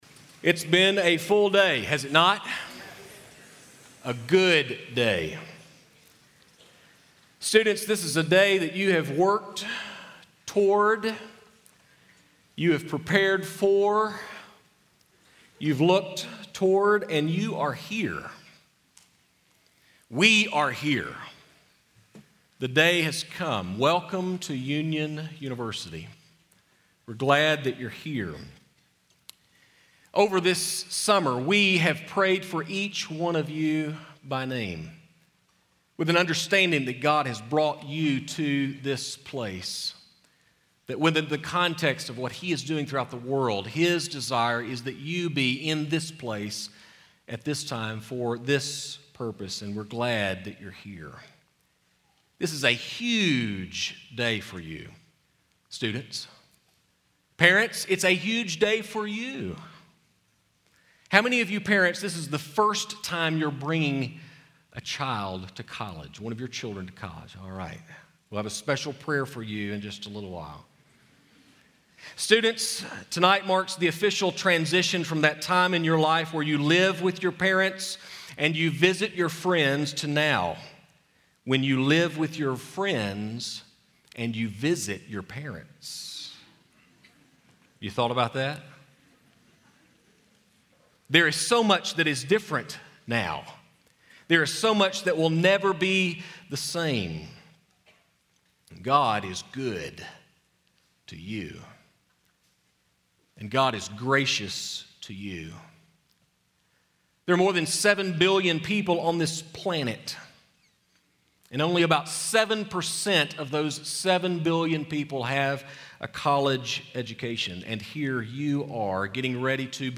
Address: "Orthos Living"